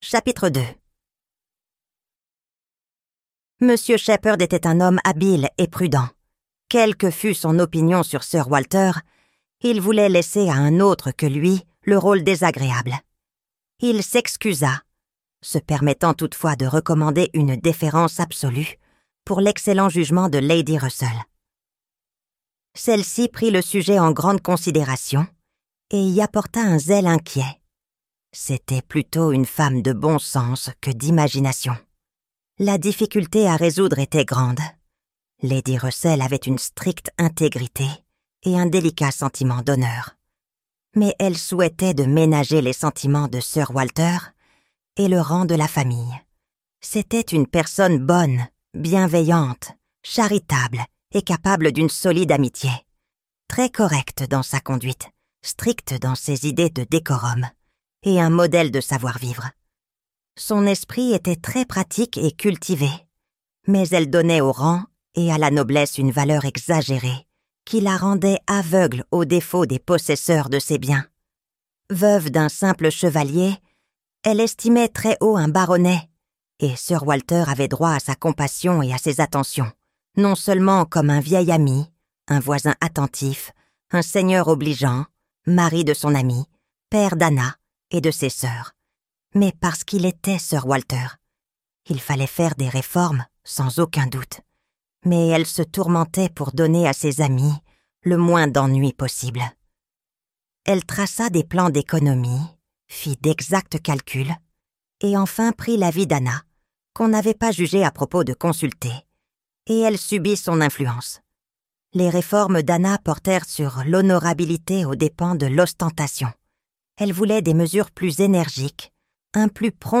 Persuasion - Livre Audio